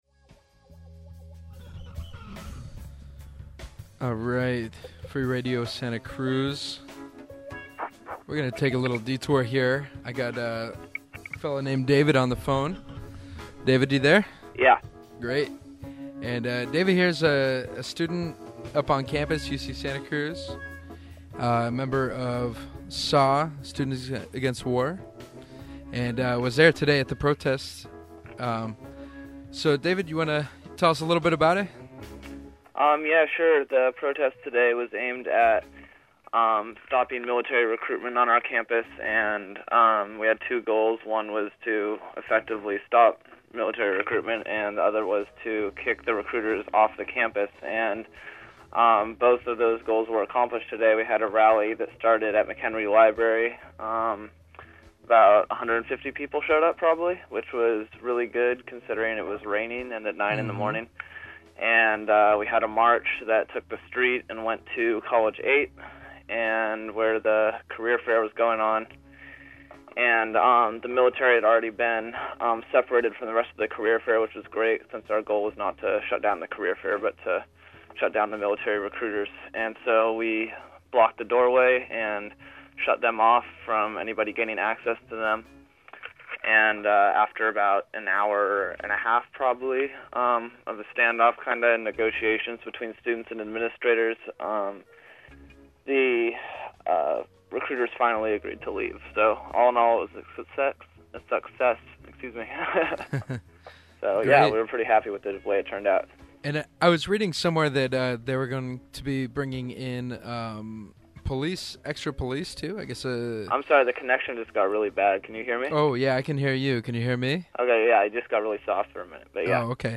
Interview was done as part of the Red X Show on Free Radio (101.1 FM) Tuesdays, 6-8pm.
I took the liberty of editing and reprocessing this audio interview, and saving as an mp3 file at a lower bit rate. Also cut ten minutes of music from the end.
red_x_recruiter_protest_interview.mp3